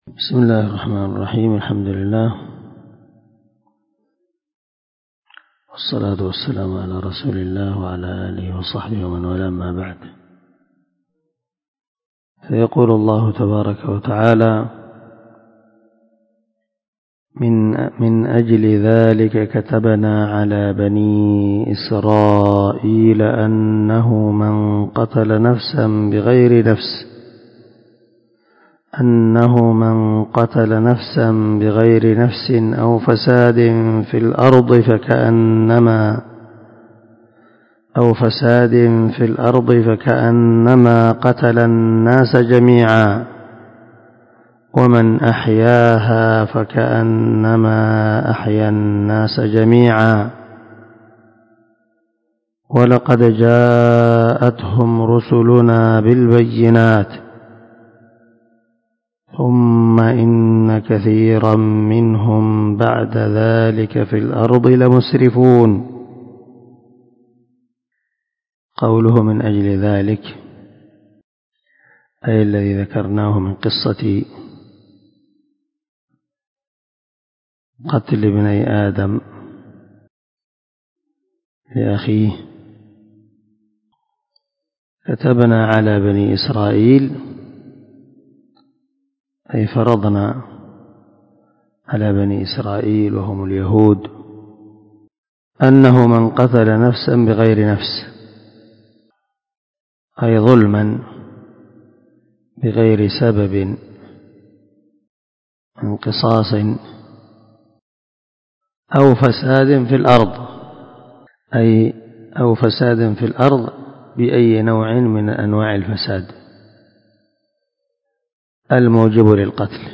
356الدرس 23 تفسير آية ( 32 ) من سورة المائدة من تفسير القران الكريم مع قراءة لتفسير السعدي